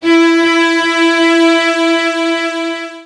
Track 11 - Cello 02.wav